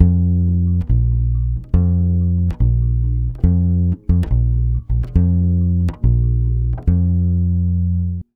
140BAS FM7 3.wav